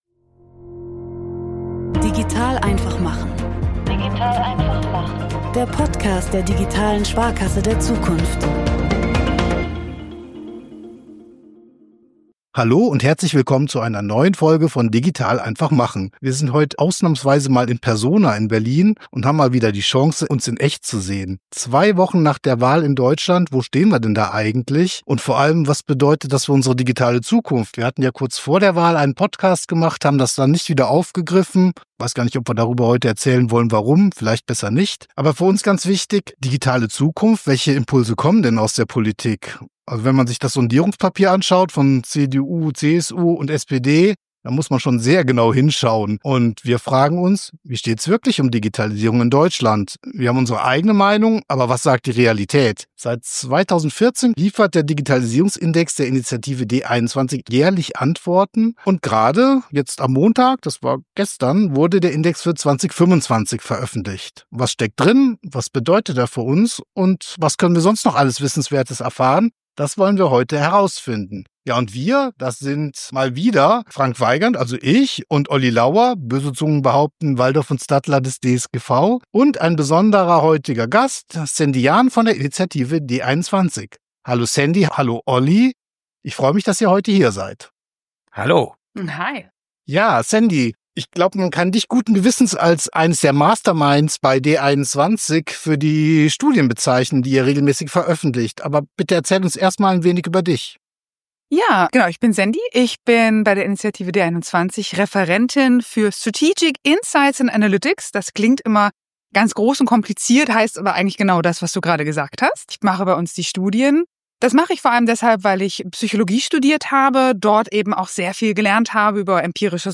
Im Gespräch mit ... 06